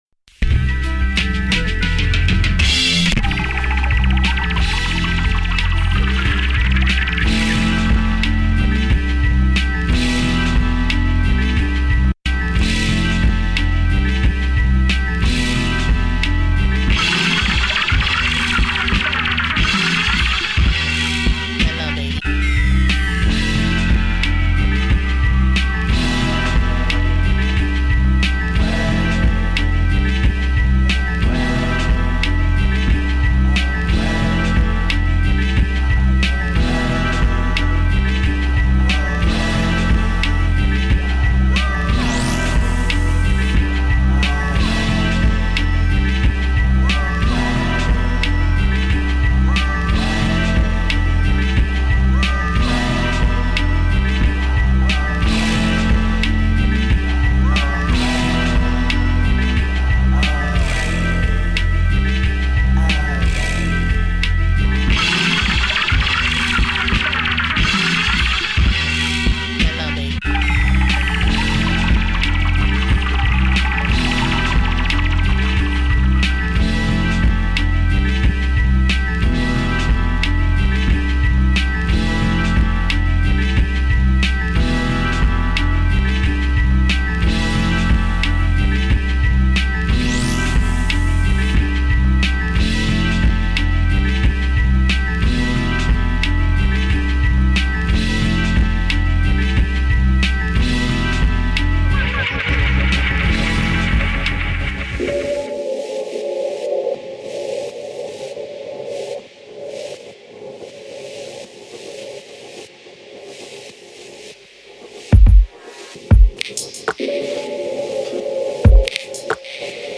experimental electronic music
Beats Electronic Experimental Rap Synth